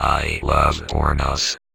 VVE1 Vocoder Phrases
VVE1 Vocoder Phrases 31.wav